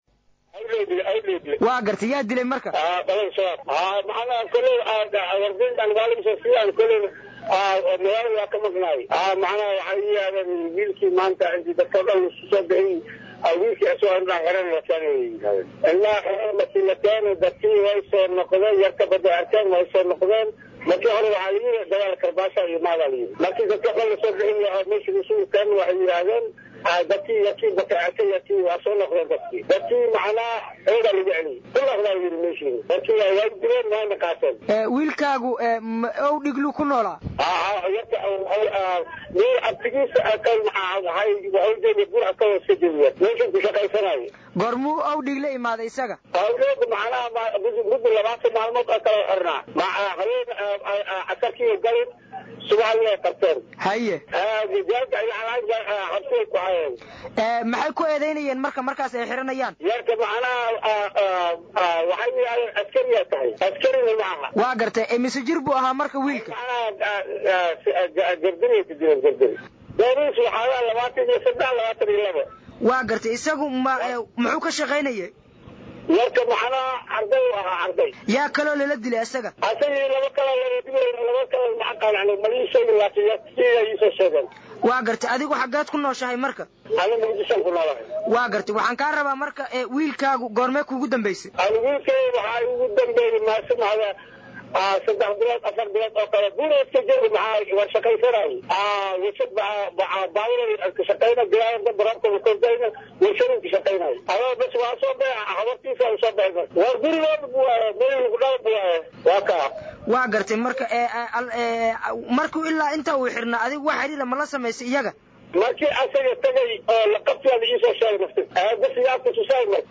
Aabahan oo ay murugo ka muuqato codkiisa ayaa sheegay in markii hore ay AlShabaab sheegeen in ragaa ay tacsiir ku fulin doonaan hase ahaatee markii ay dadka soo xaadireen fagaaraha ay sheegeen in toogasho ay ku xakuman yihiin halkaasna lagu dilay.